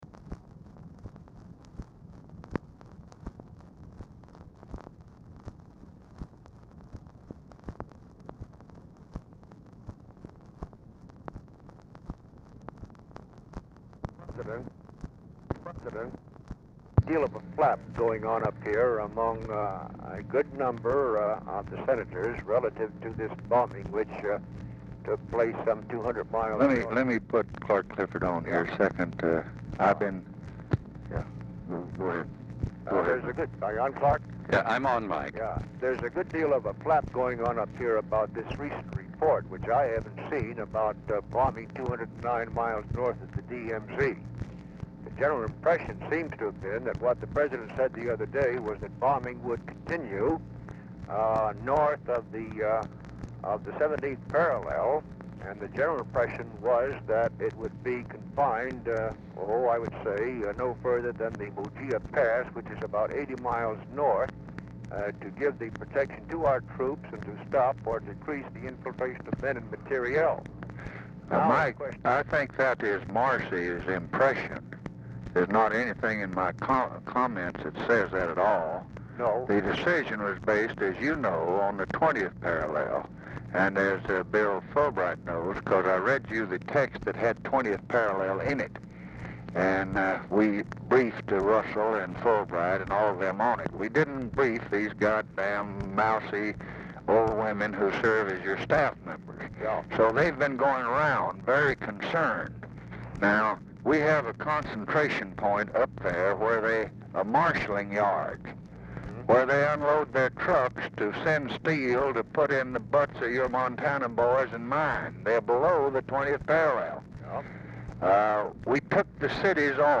Telephone conversation
0:15 MACHINE NOISE PRECEDES CALL
Format Dictation belt
Location Of Speaker 1 Mansion, White House, Washington, DC